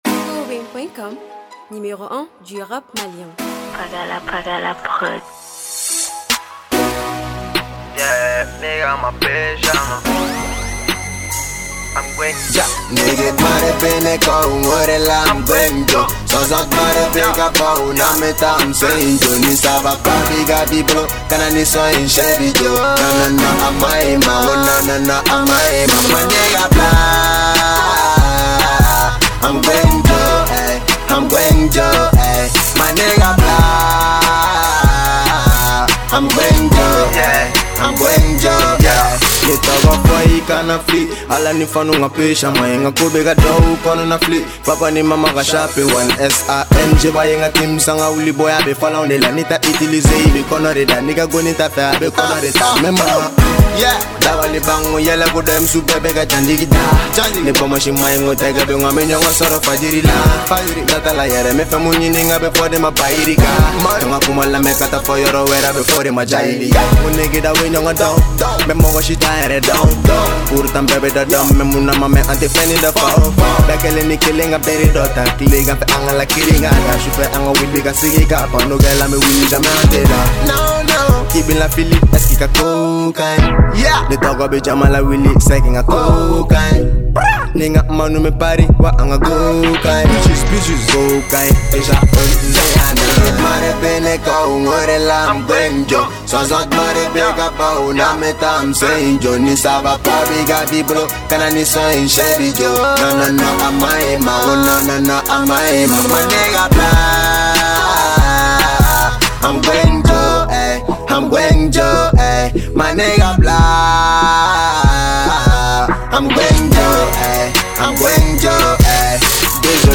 musique Mali trap.